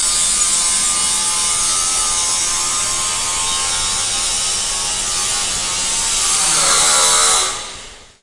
手持式研磨机1
描述：手持式研磨机切割金属的声音